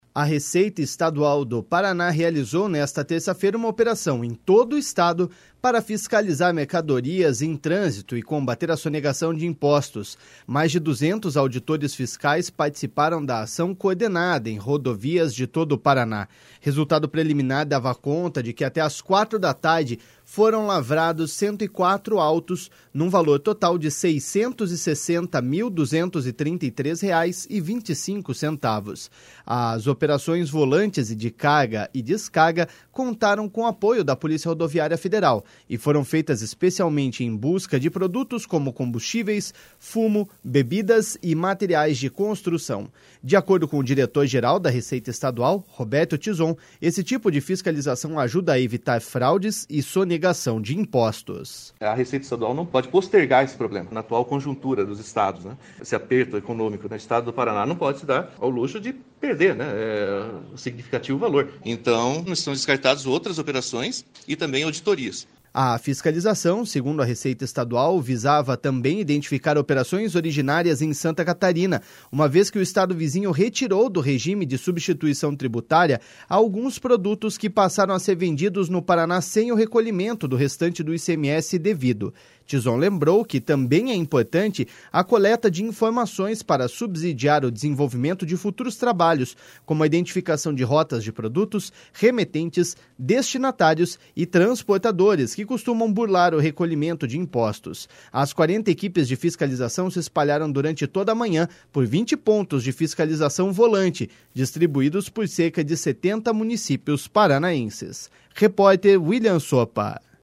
De acordo com o diretor-geral da Receita Estadual, Roberto Tizon, esse tipo de fiscalização ajuda a evitar fraudes e sonegação de impostos.// SONORA ROBERTO TIZON.//